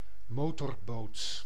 Ääntäminen
IPA: [və.dɛt]